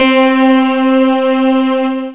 BELL SYNTH.wav